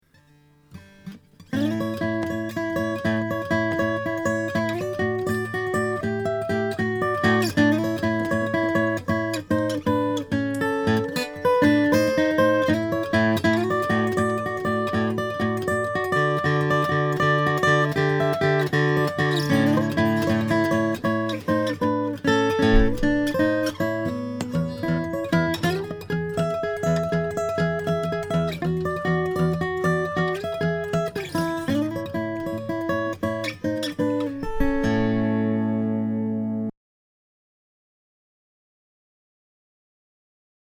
Tone is hard to describe, but with red spruce/ white oak, it's a very fundamental tone, really bluesy.